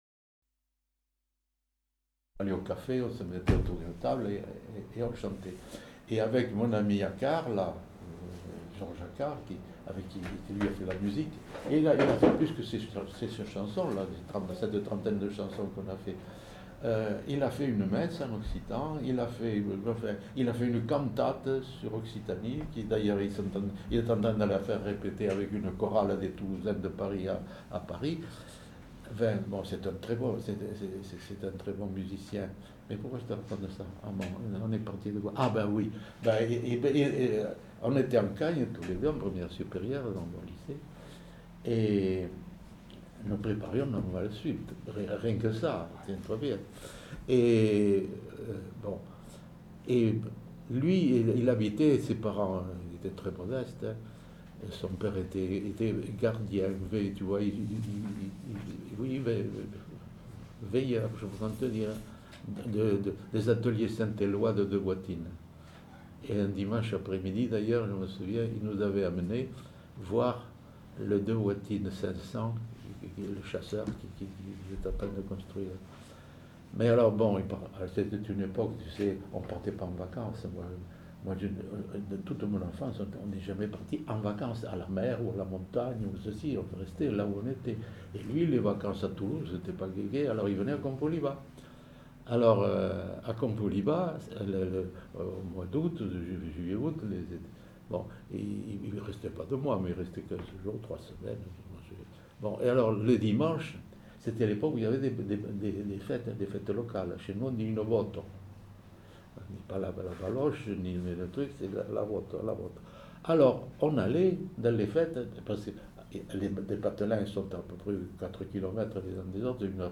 Aire culturelle : Pays toulousain ; Rouergue
Genre : récit de vie